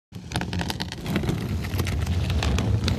Drag-plastic-trash-can-on-pavement-scrapes-2.mp3